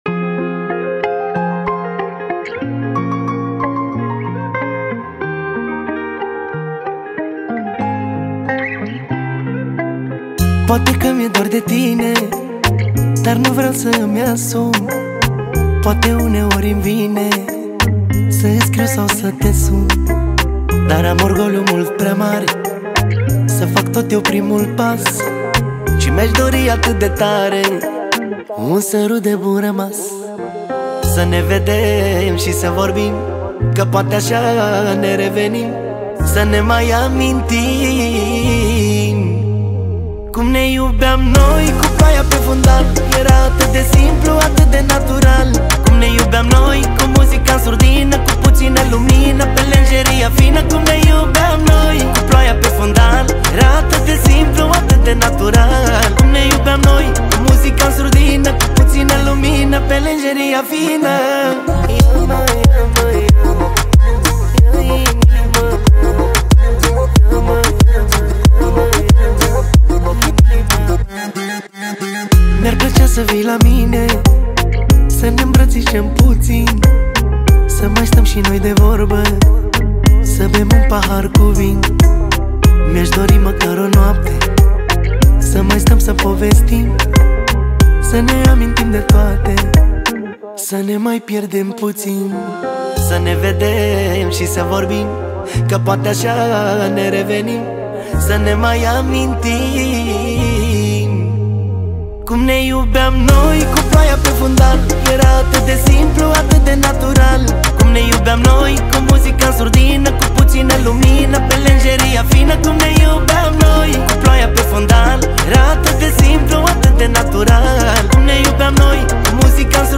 Muzica Usoara